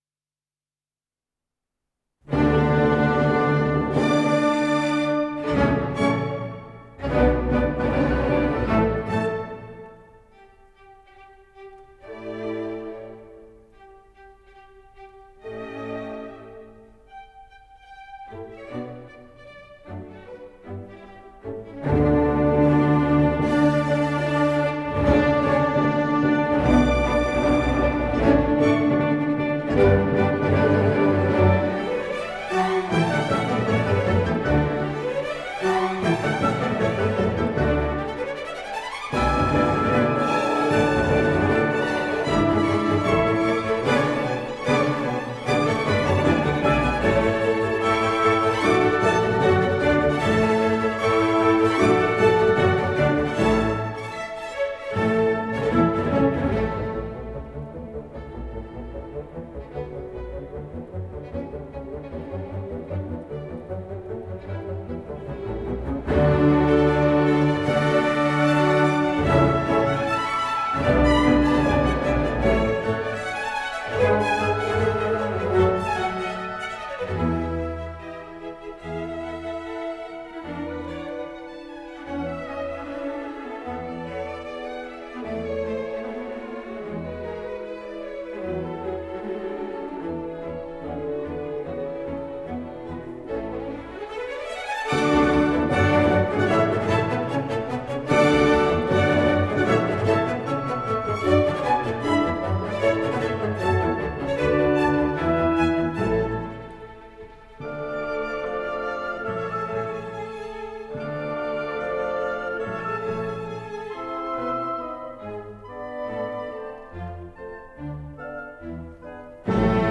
» 1 - Symphonies
Allegro Con Spirito